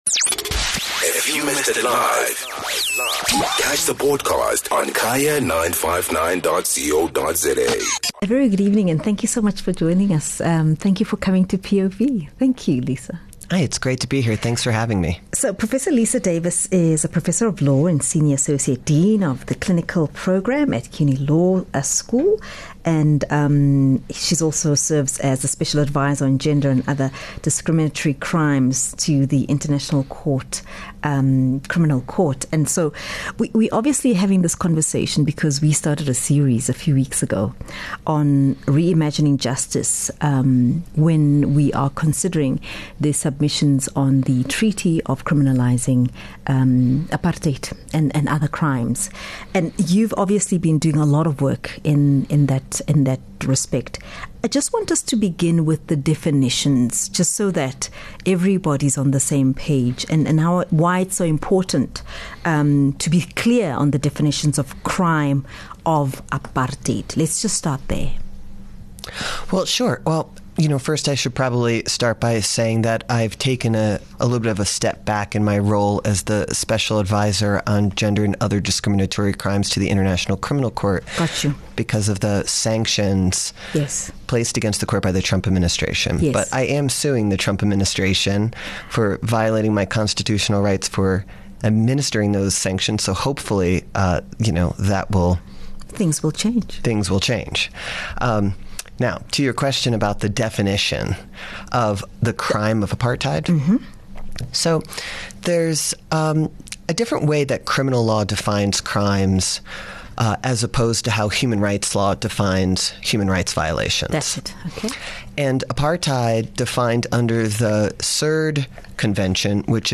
is joined in studio